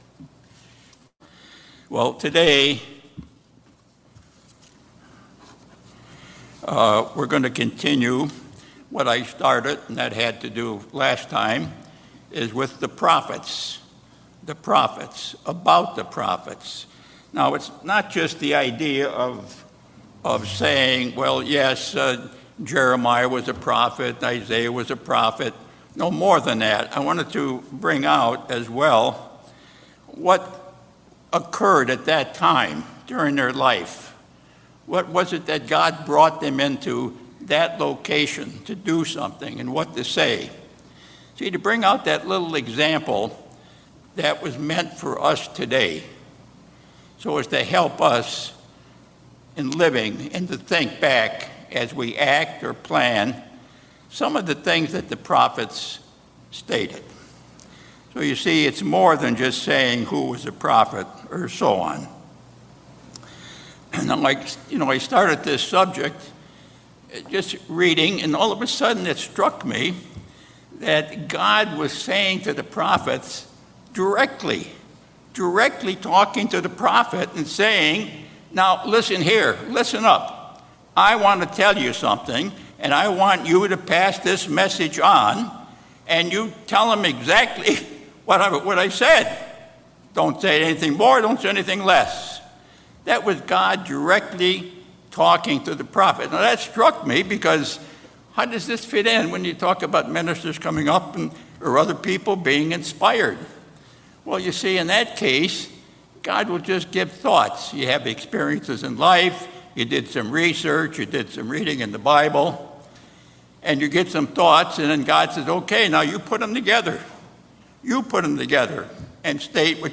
Sermons
Given in Los Angeles, CA Bakersfield, CA